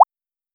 ajout des sfx de recharge
recharge_capsule_7.wav